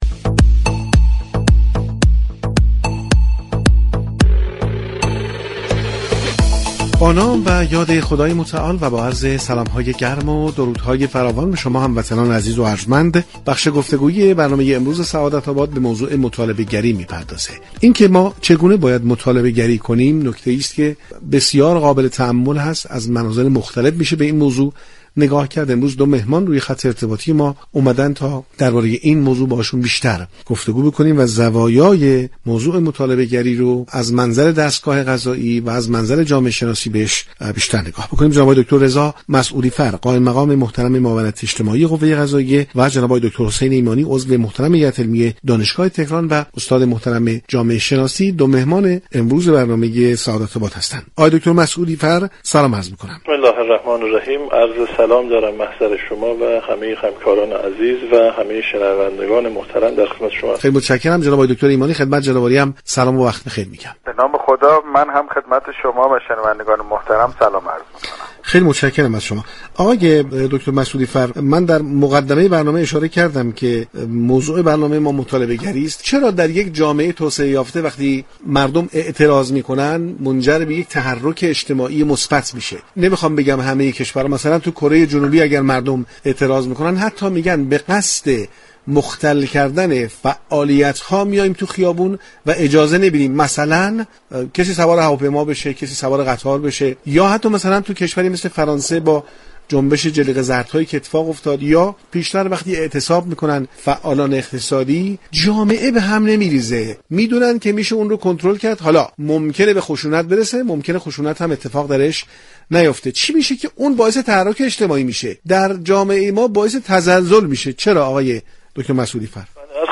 در رابطه با مطالبه گری از منظر قوه قضاییه و جامعه شناسی با میزگرد سعادت آباد گفتگو كردند.